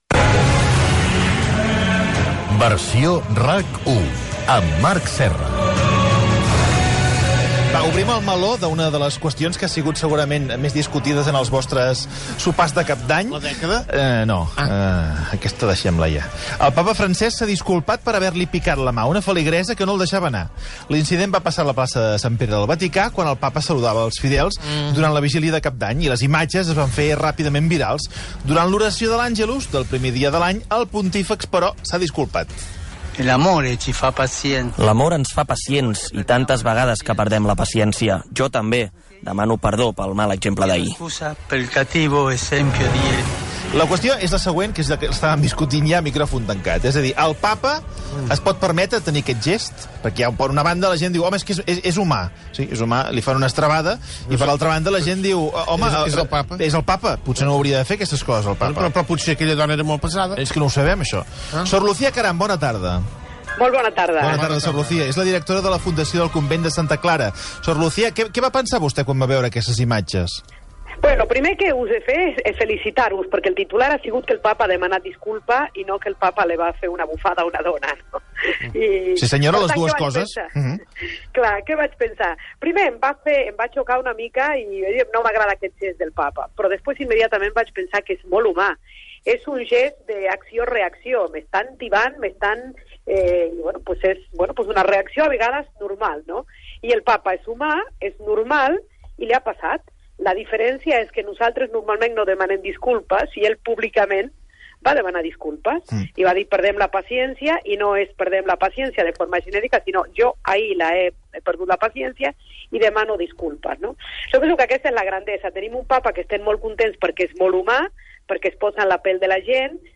Trucada telefònica a Sor Lucia Caram per comentar el fet.